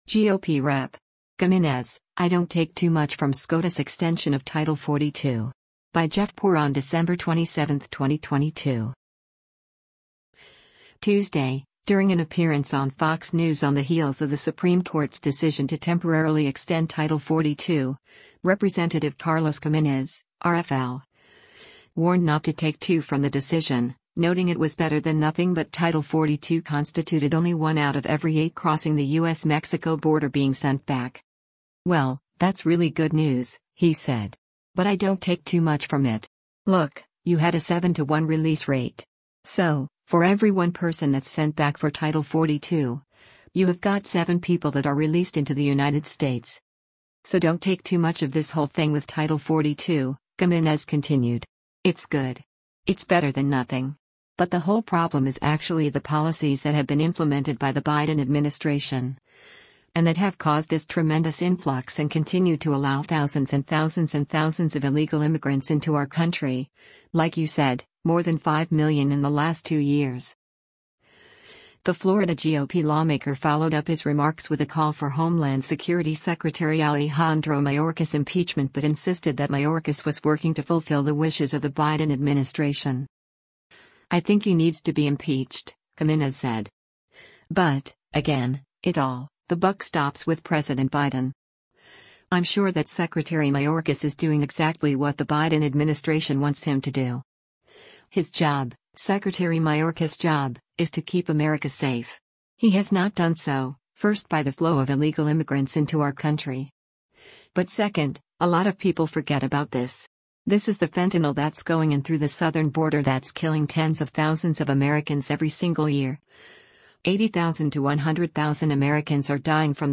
Tuesday, during an appearance on Fox News on the heels of the Supreme Court’s decision to temporarily extend Title 42, Rep. Carlos Gimenez (R-FL) warned not to “take too from” the decision, noting it was better than nothing but Title 42 constituted only one out of every eight crossing the U.S.-Mexico border being sent back.